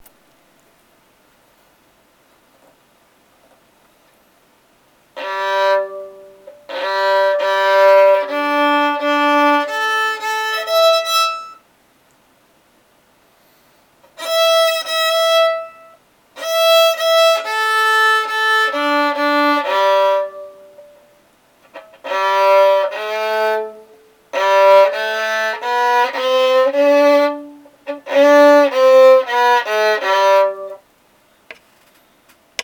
It took me awhile to get used the Fiddlerman synthetic strings after playing my Apprentice with the steel Prelude strings.
Ouch, that's painful. Try to imagine what it would sound like played by someone who knows what they're doing.